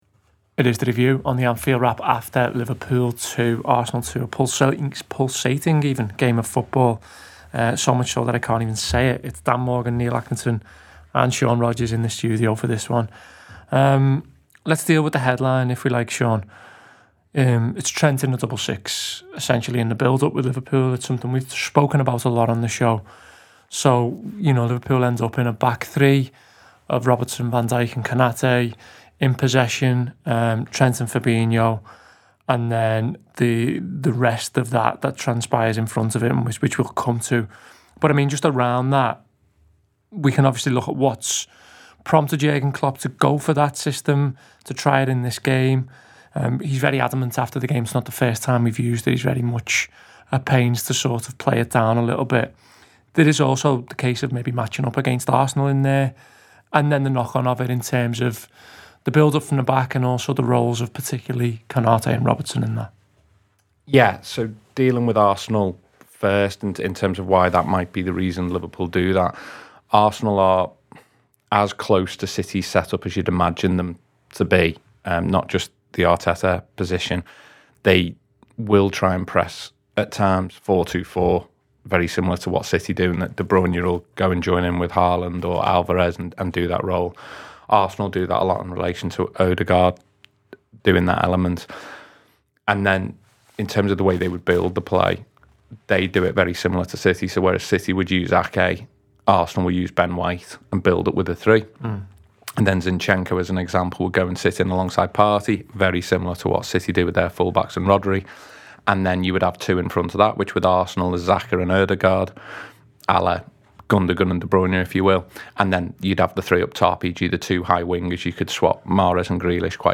Below is a clip from the show – subscribe for more review chat around Liverpool 2 Arsenal 2…